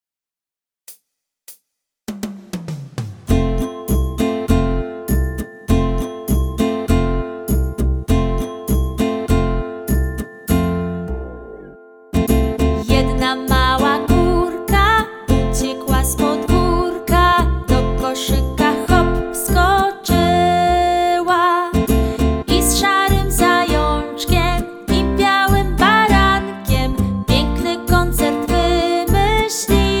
utwór w wersji wokalnej